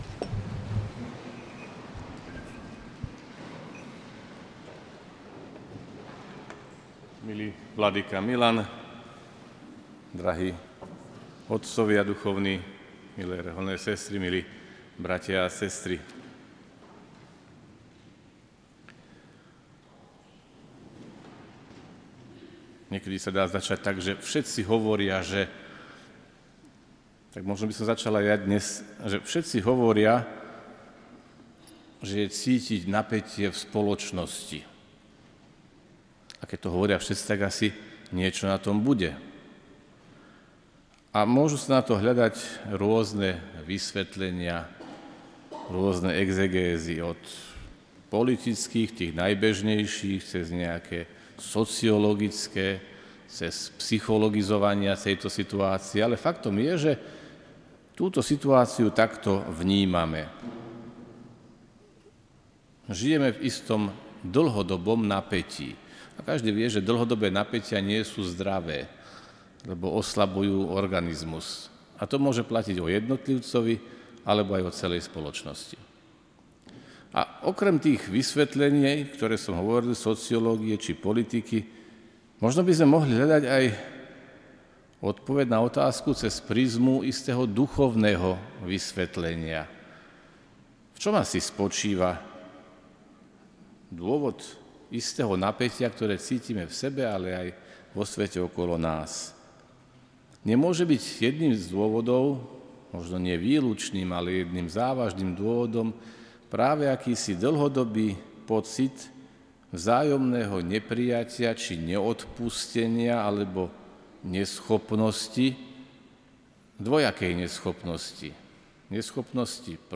Svätú liturgiu viedli vladyka Cyril Vasiľ, SJ a vladyka Milan Chautur, CSsR s kňazmi.